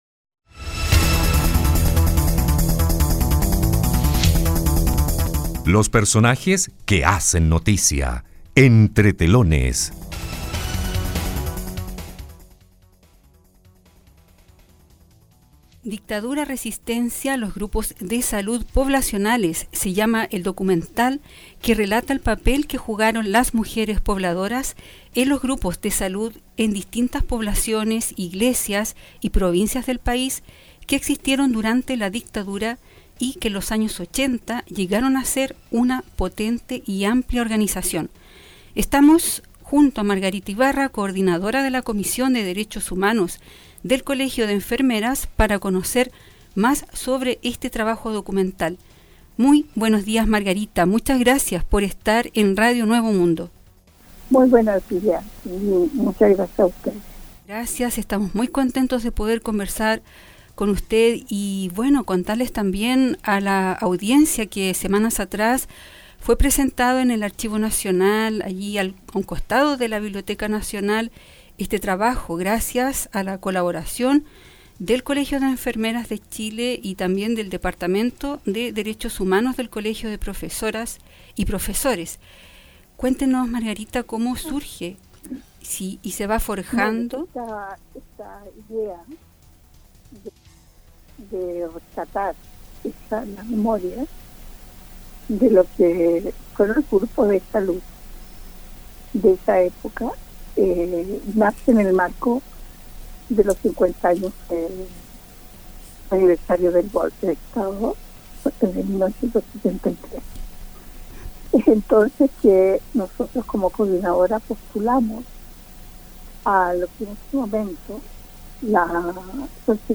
[Podcast / Entrevista] Documental resalta el trabajo de mujeres en los grupos de salud durante la dictadura - Radio Nuevo Mundo